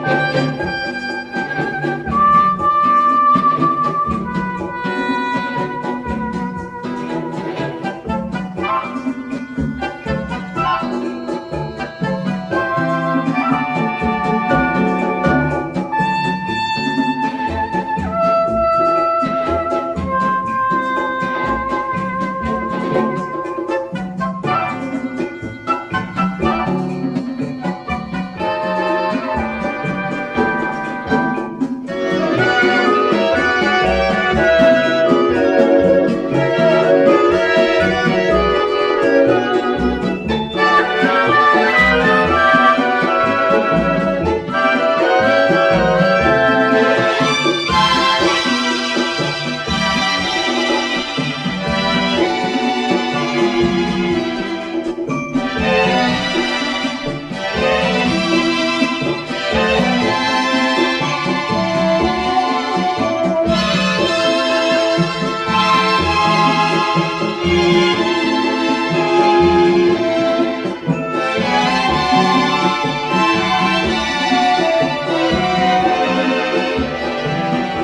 EASY LISTENING / EASY LISTENING / LATIN